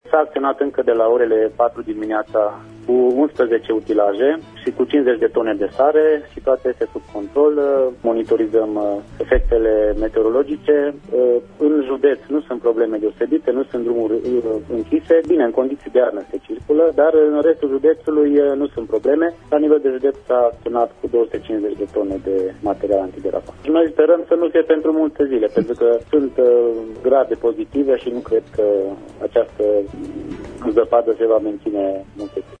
Prefectul judeţului, Jean-Adrian Andrei, pentru emisiunea Pulsul zilei: